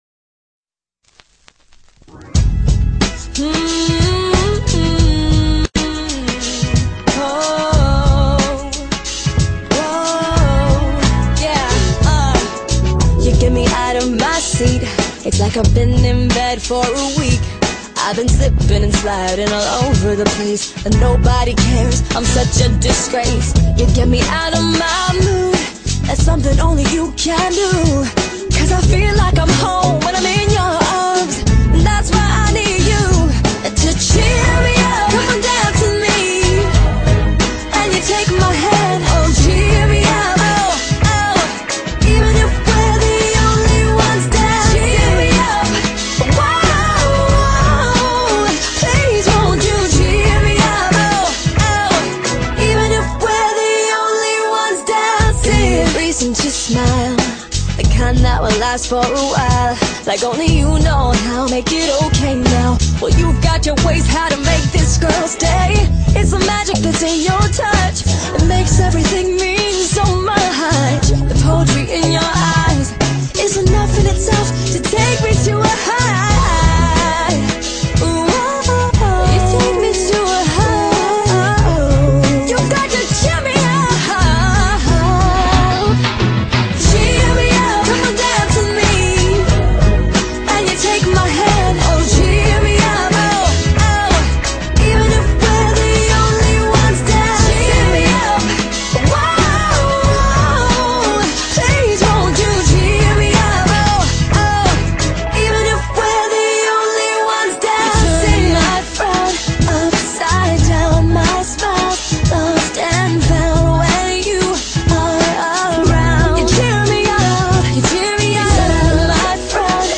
GÊNERO: POP DANCE